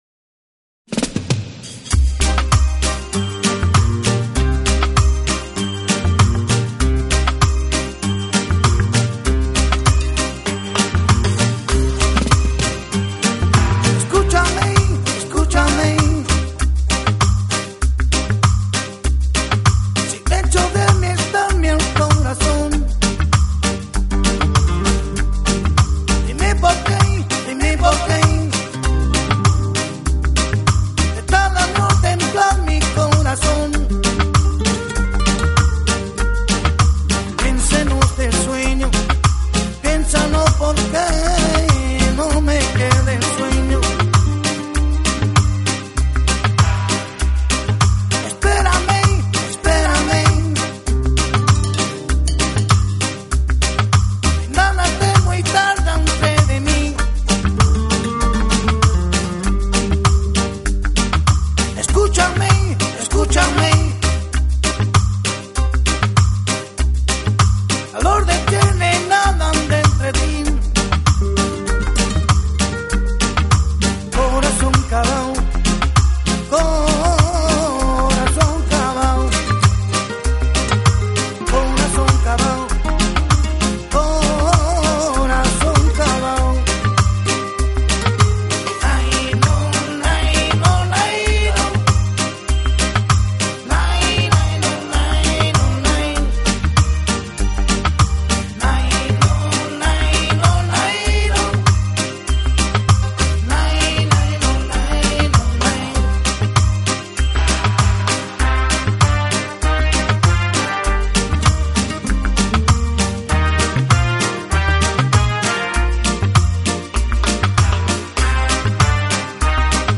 Genre: Latin / Flamenco Rumba
Genre: Flamenco Rumba (弗拉明戈伦巴)，Latin (拉丁)